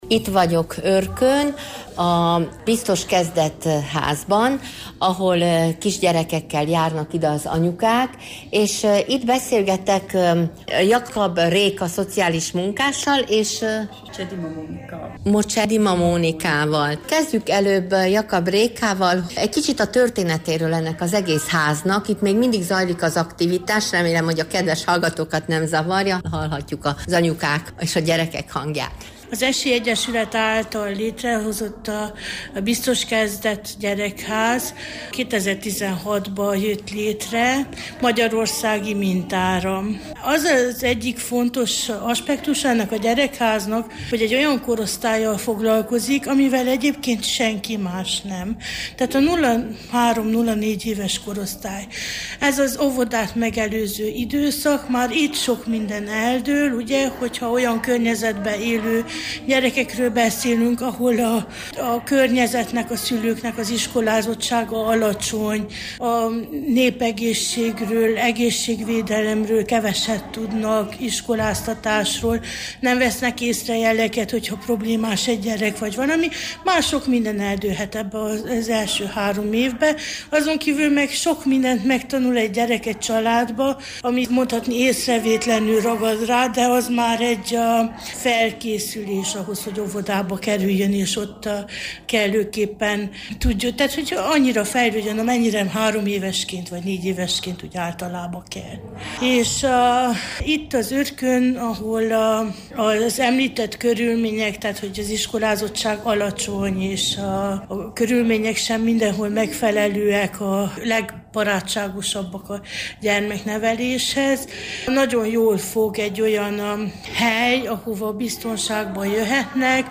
Őrkőn készült még egy riport a Ringató Biztos Kezdet Gyermekházban, ahol egészen kicsi gyerekeket fogadnak, édesanyjukkal járnak ide napi foglalkozásra a 0 és 4 év közötti kicsinyek.
Kettőjükkel beszélgettünk az őrkői édesanyák gondjairól és az otthon nyújtotta lehetőségekről.